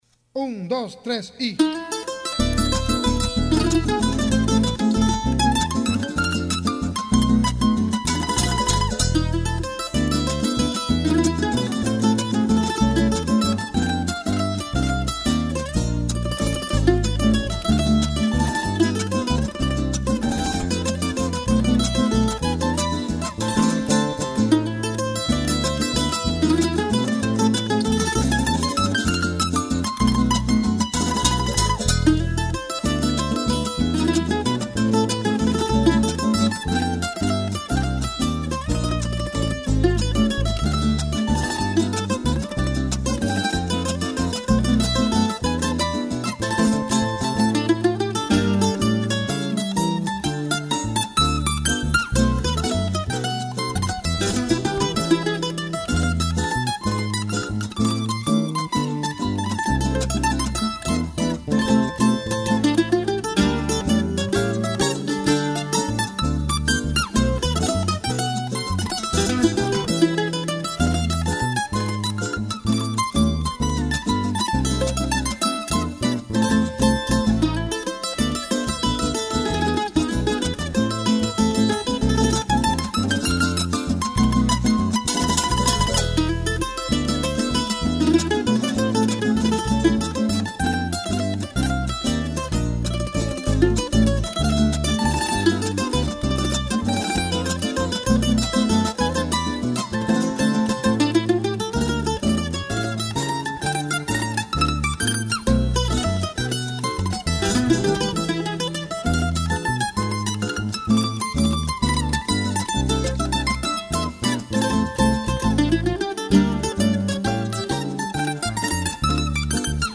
Vals venezolano